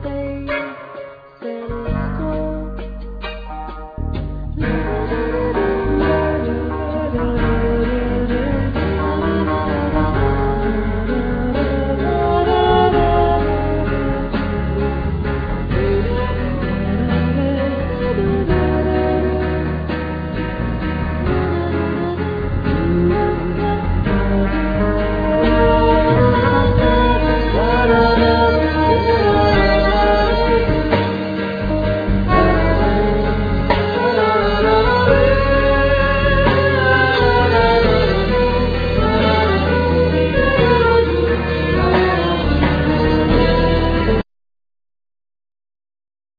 Accordeon,Vocal
Violin,Viola
Keyboards,Vocals
Electric & Acoustic Bass,Guiro
Vibraphone,Marimba,Glockenspiel,Percussion
Drums,Percussion